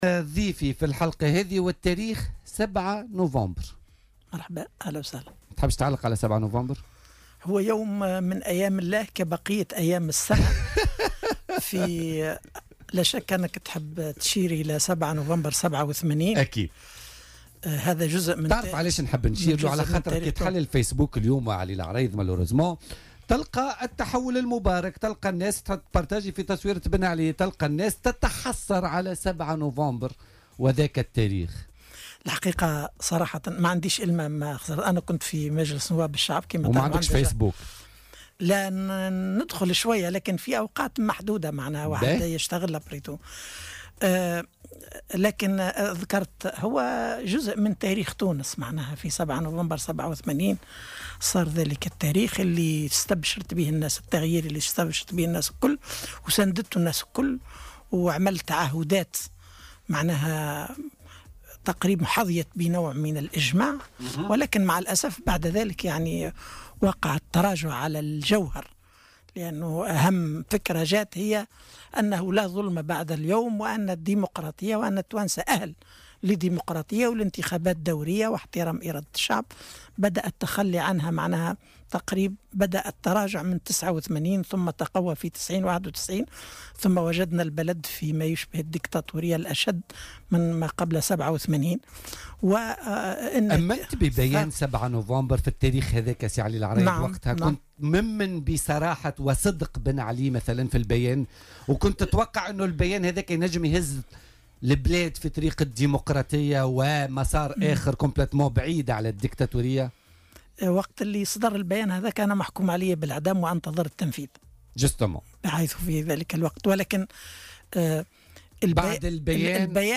وأضاف ضيف "بوليتيكا" اليوم انه عند صدر بيان 7 نوفمبر 1987 كان الحدث الذي يمثل التغيير الذي رحب به كل الناس وقتها قبل حصول تراجع عن جوهر التغيير.